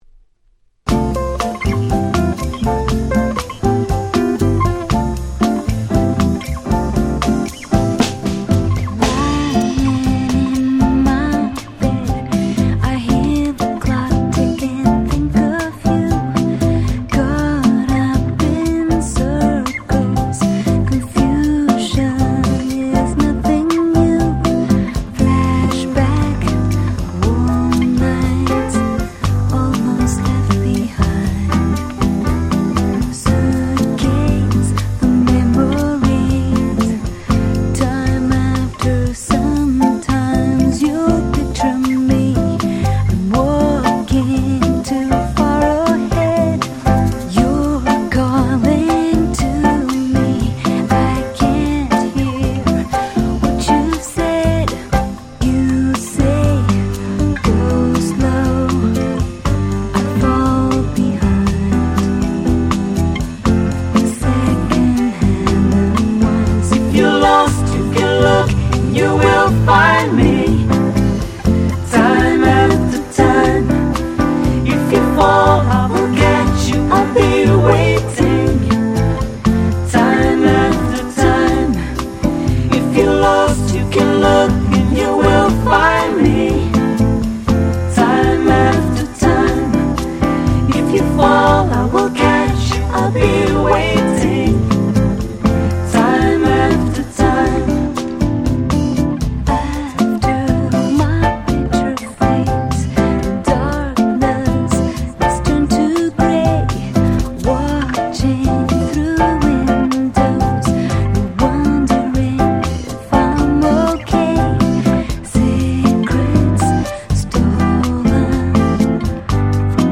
95' Very Nice Bossanovaカバー！！
日曜日の午後かなんかにまったり聴きたい感じですね〜！
ボサノヴァ 90's R&B